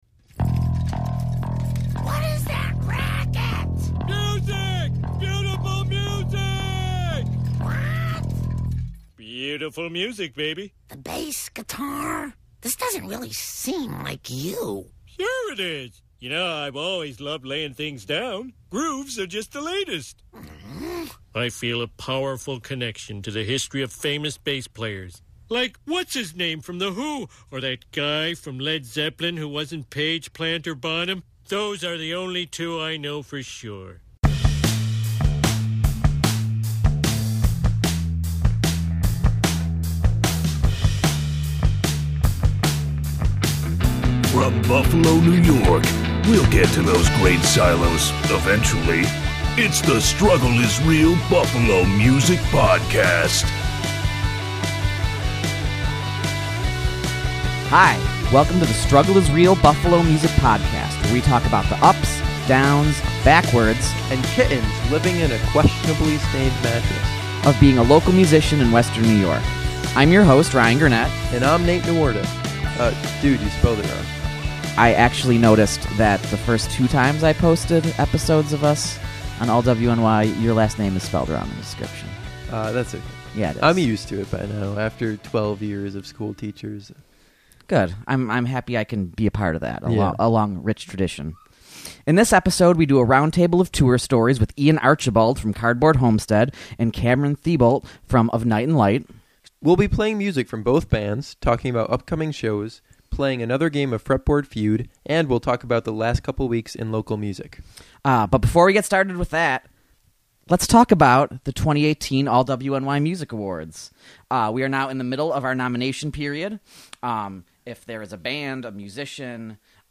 We’ll play music from our both guests and they will join us for a game of Fretboard Feud, where we talk about the worst venues in Buffalo and the worst thing about drummers and more!